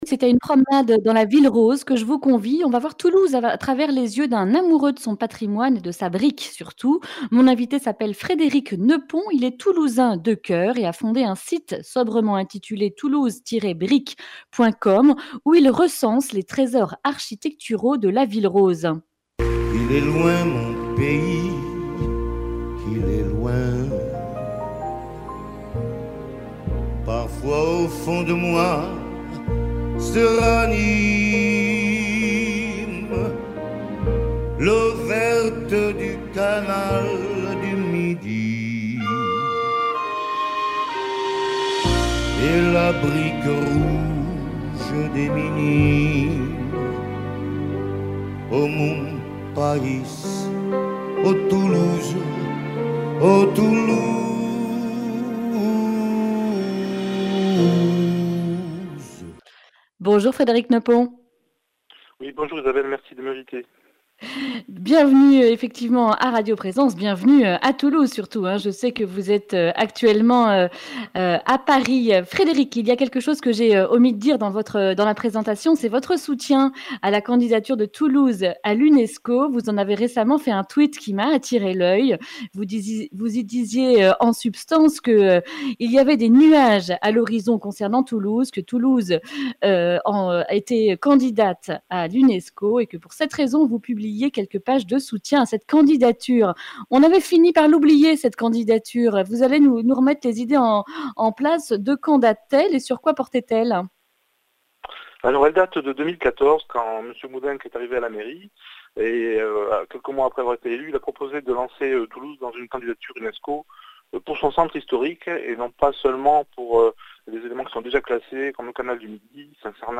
Accueil \ Emissions \ Information \ Régionale \ Le grand entretien \ Il était une fois la brique à Toulouse...